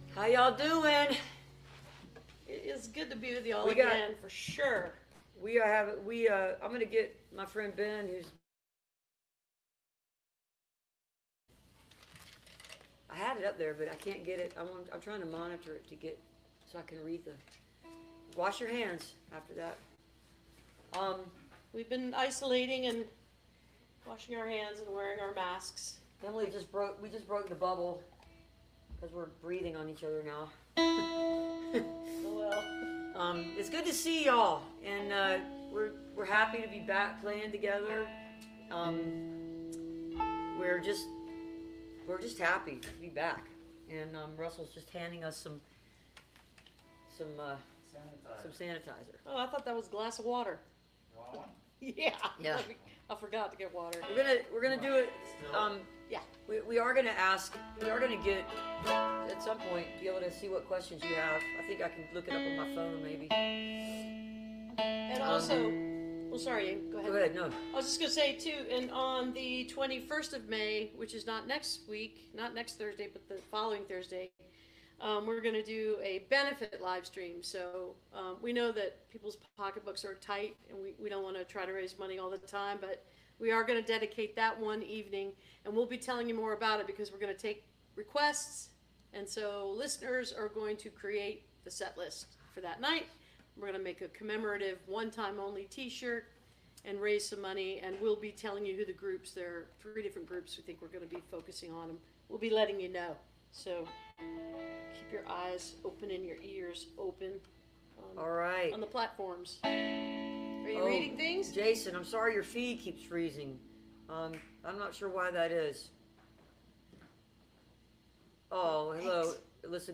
lifeblood: bootlegs: 2020-05-07: song request livestream - facebook/instagram/youtube
(captured from the facebook live video stream)
03. talking with the crowd (2:29)